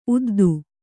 ♪ uddu